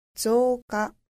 • ぞうか
• zouka